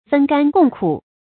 分甘共苦 注音： ㄈㄣ ㄍㄢ ㄍㄨㄙˋ ㄎㄨˇ 讀音讀法： 意思解釋： 同享幸福，分擔艱苦。